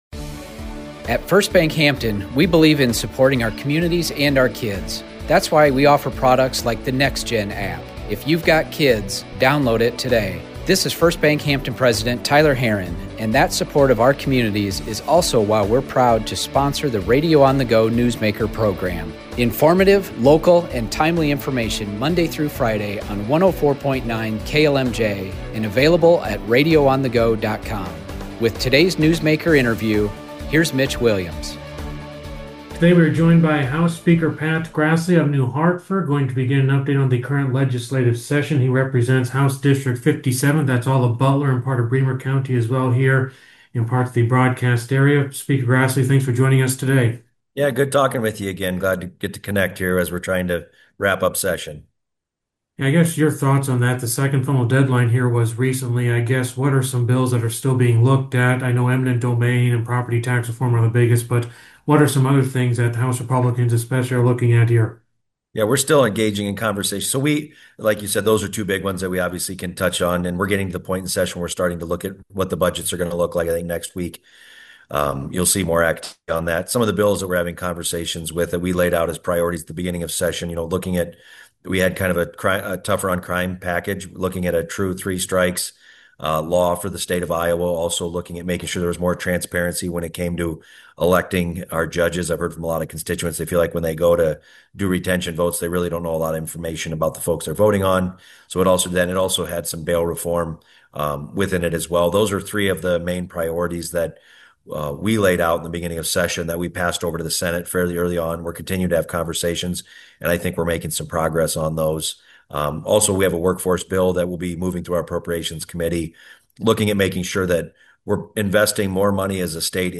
Full interview below
newsmaker-april-3-pat-grassley.mp3